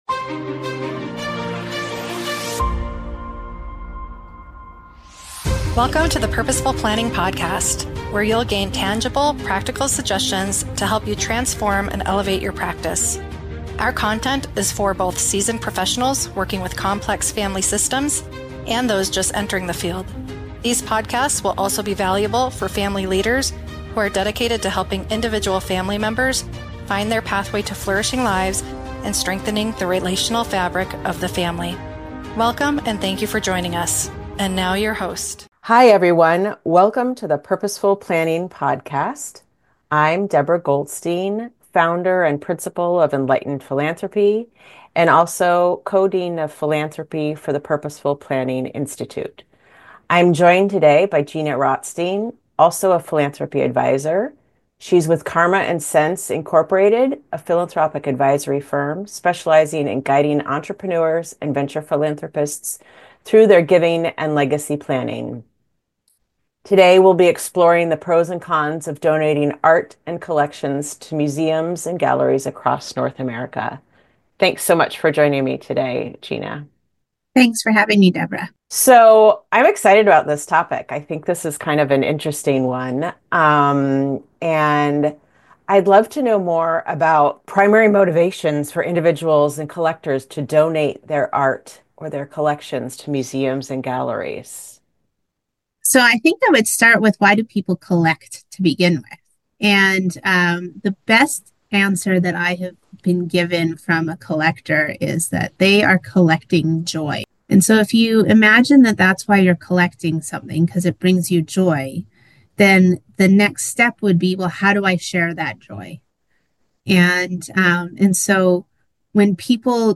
Join us for an insightful conversation on the art of giving wisely.